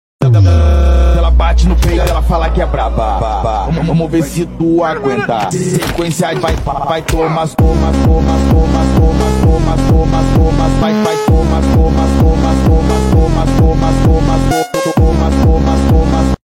📺 Me and bro arguing sound effects free download